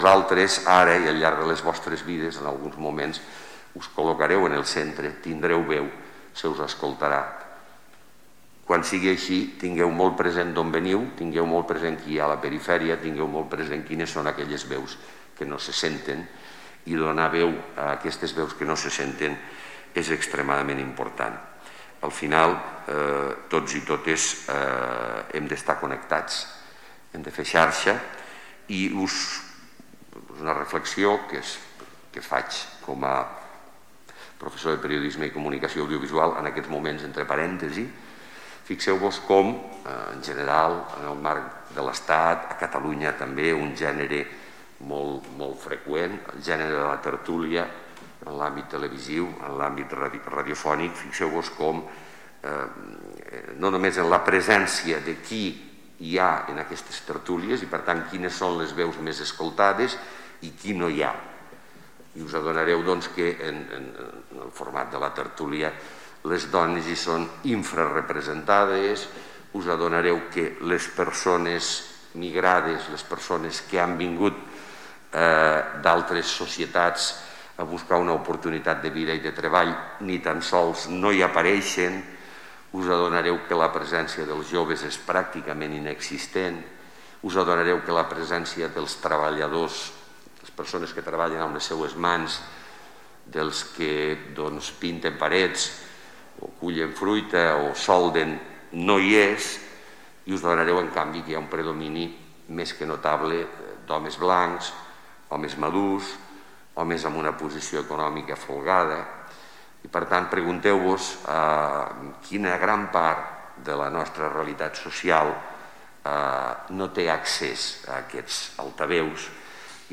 tall-de-veu-de-lalcalde-miquel-pueyo-sobre-el-concurs-periodistic-josep-pernau